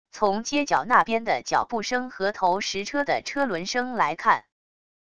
从街角那边的脚步声和投石车的车轮声来看wav音频